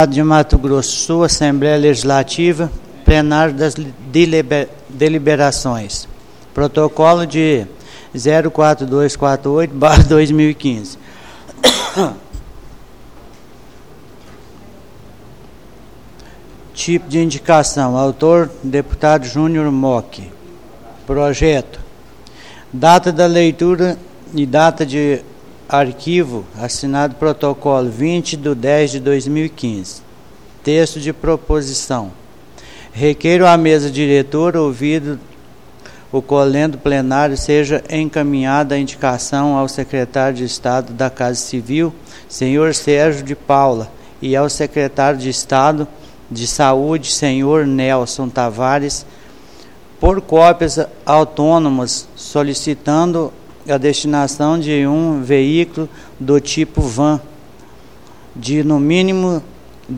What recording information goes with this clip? Áudio Sessão Ordinária Nº 36 (3ª parte)